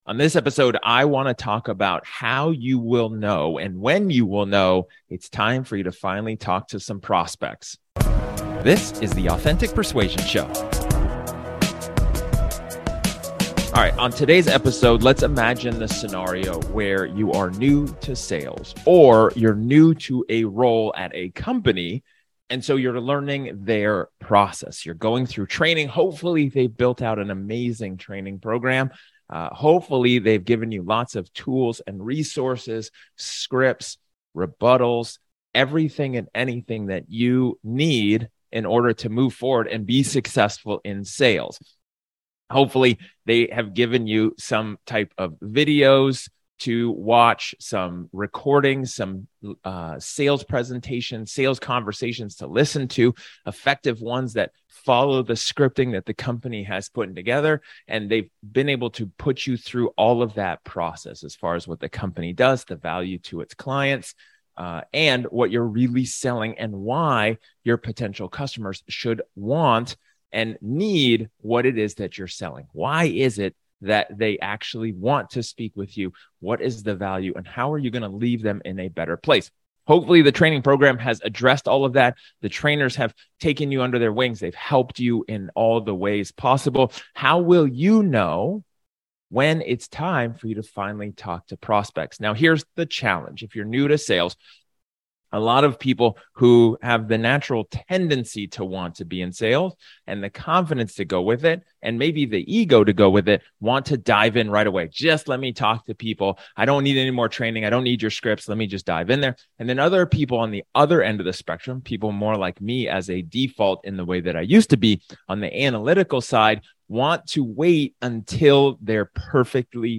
In this solo episode, I talk about when should be the perfect time when you should go and speak to prospects.